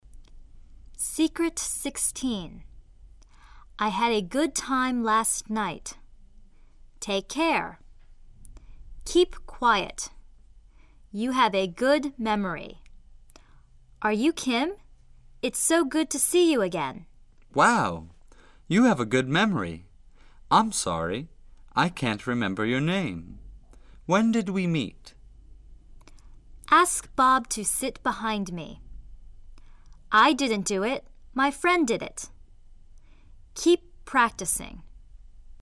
辅音和辅音相邻的时候，发音的最大特点就是：前虚后实。
*Ask-Bob-to sit-behind-me.                  让鲍勃坐在我后面。【省略了四个音】
【特别说明】美国人说英语另外的特点是：好听、偷懒、省力、经济原则。连音、略音就是这些特点的体现。